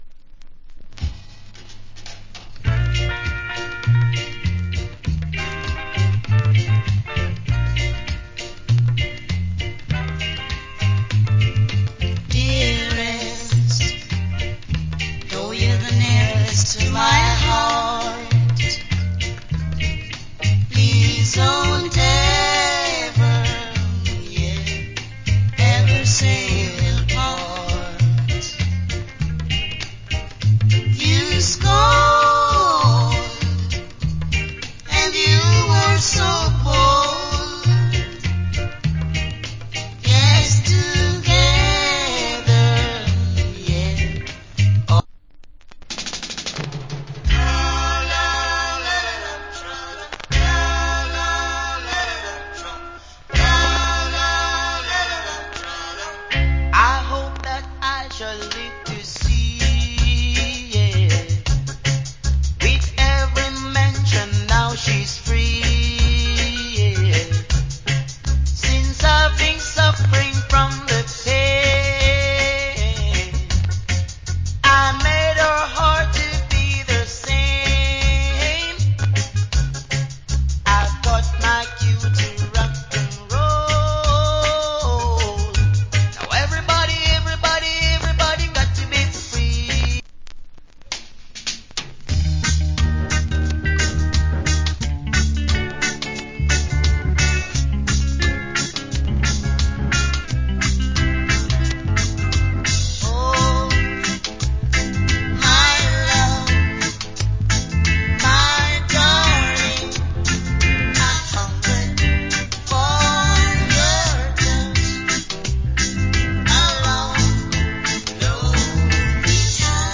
Nice Reggae.